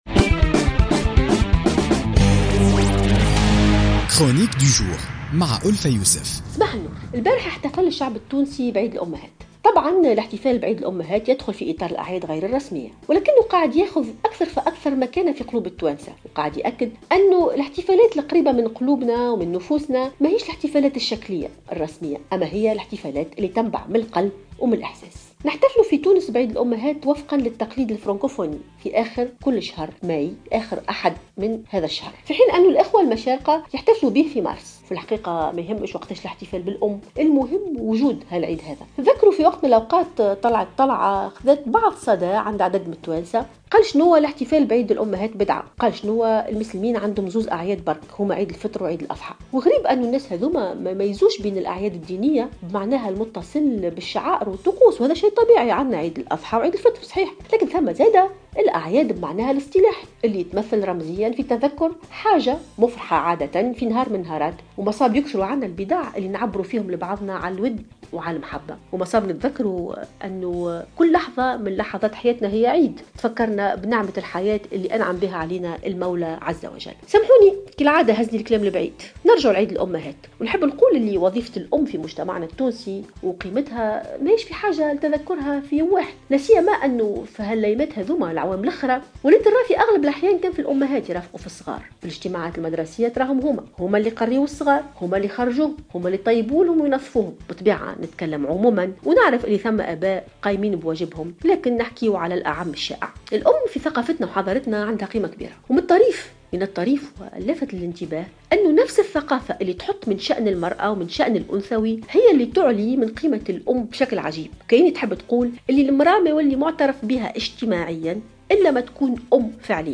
تطرقت الكاتبة التونسية ألفة يوسف في افتتاحية إذاعة "الجوهرة أف أم" اليوم الاثنين إلى احتفاء التونسيين بعيد الأمهات الذي يتزامن مع آخر يوم أحد من كل شهر ماي بحسب التقليد الفرانكفوني.